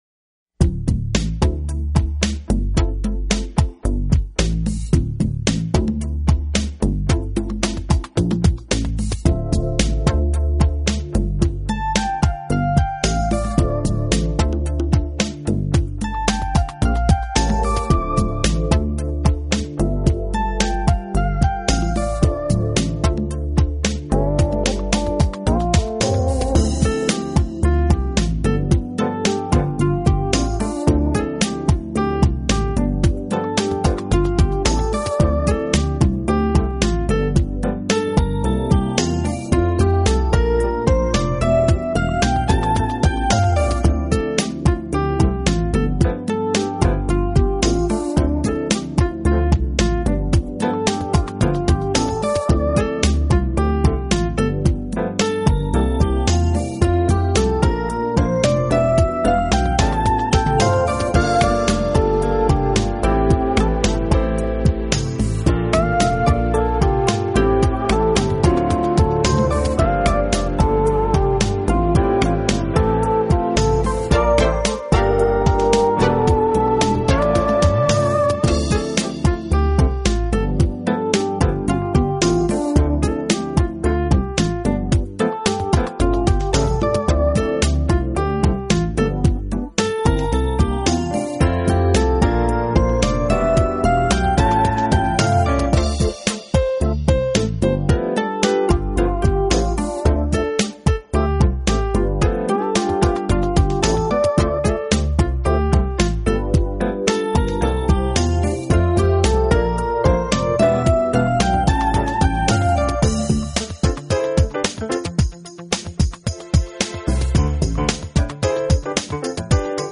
Genre: Smooth Jazz
Popular and contemporary.